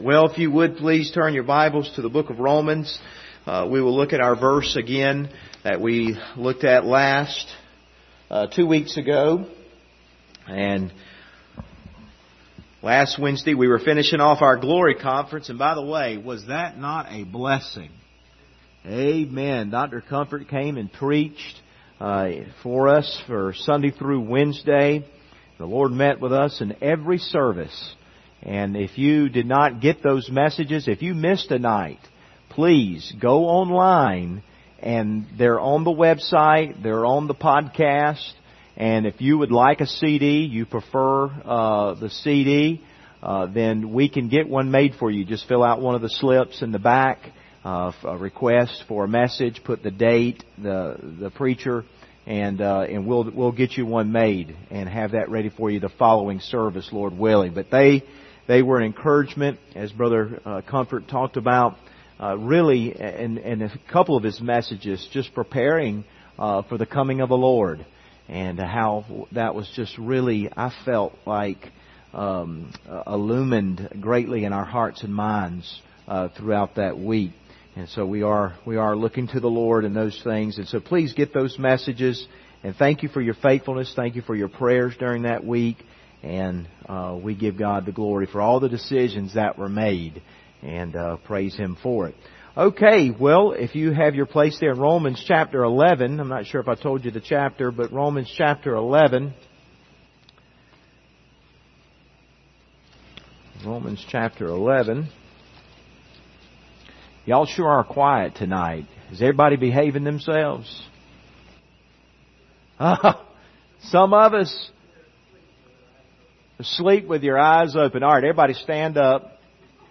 Service Type: Wednesday Evening Topics: wisdom of God